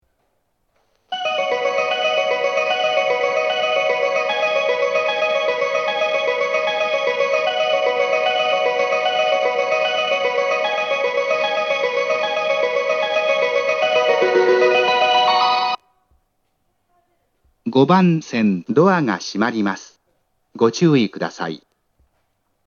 発車メロディー
余韻切りです。余韻切りが大変多いです。
6番線の放送が被りやすいです。